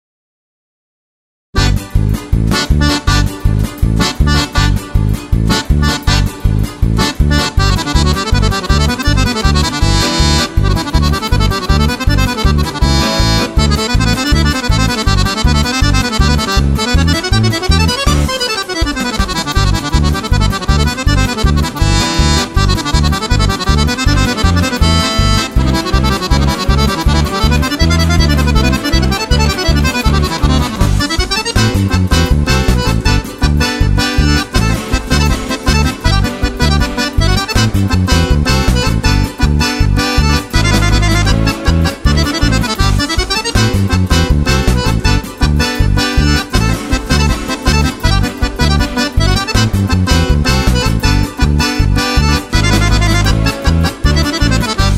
Playback - audio karaoke für Akkordeon